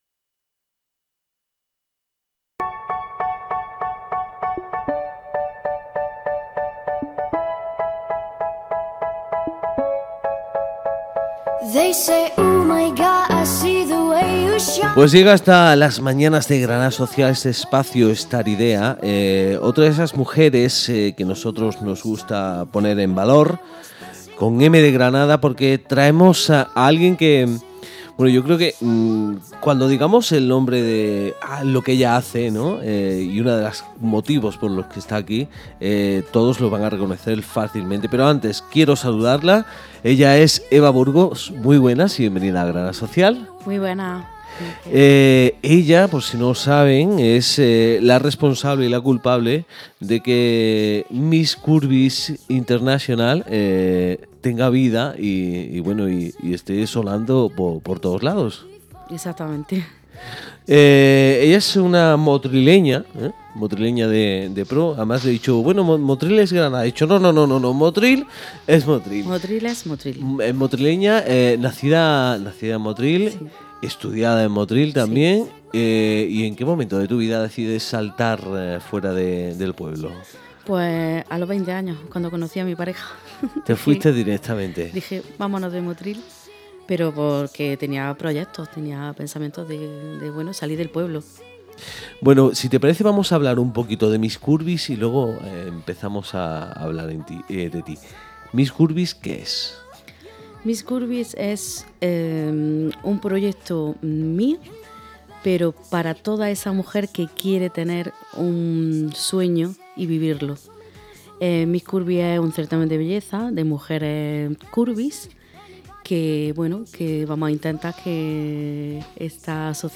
Esta es la conversación que hemos tenido con una mujer de bandera, Una todo terreno subida en las pasarelas.